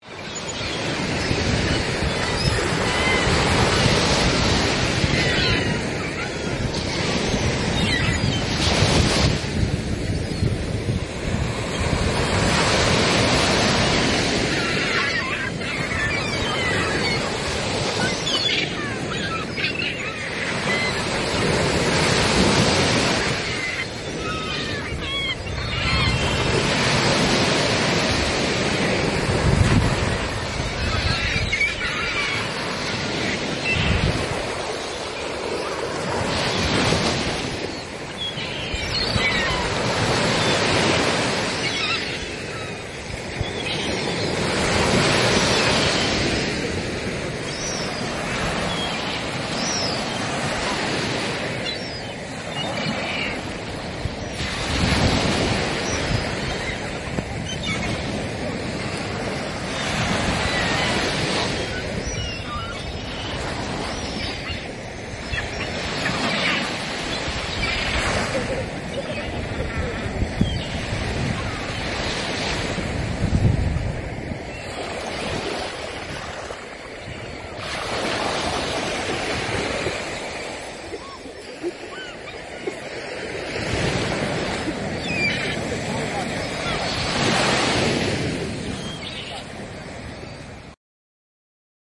自然 " 米尔诺的海鸥
描述：在Mielno（波兰）的海滩上波罗的海的海鸥的spontan记录
Tag: 海滩 音场 海浪 海鸥 现场记录